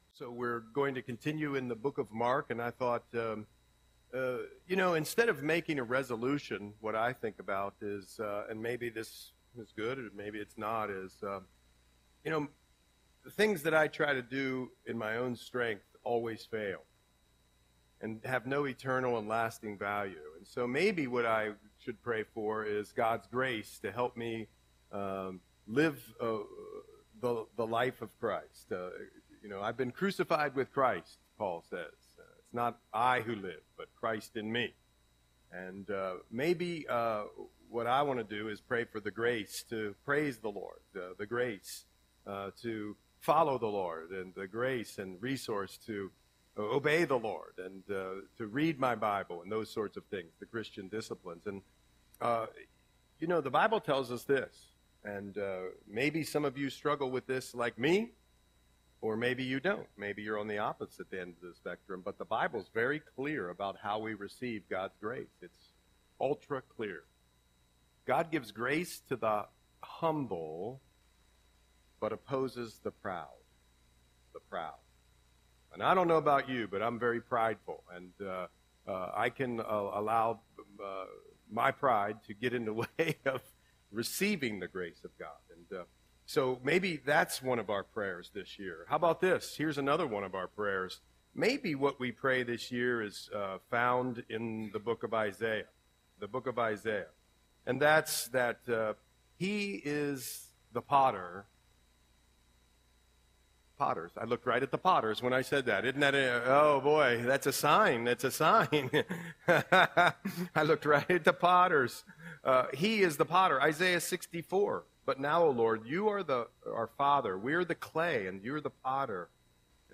Audio Sermon - January 5, 2025